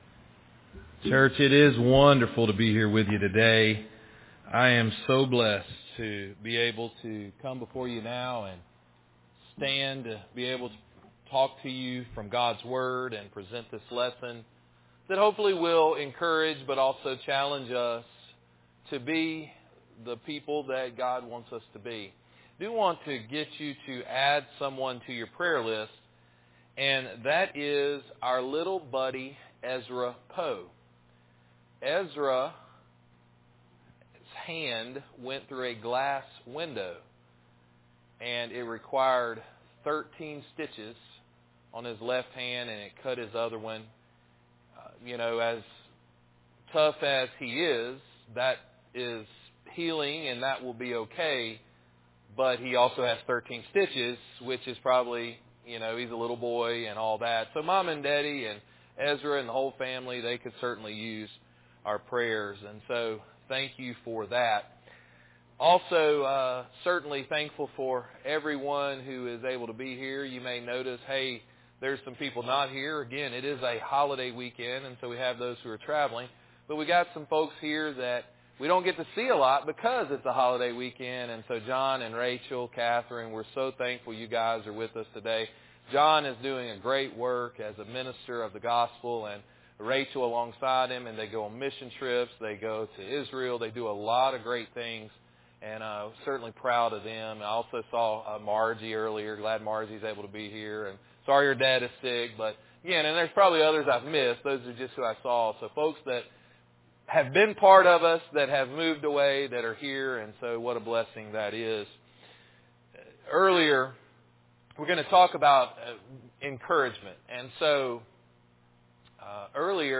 Encourage Sermon – Azalea City Church of Christ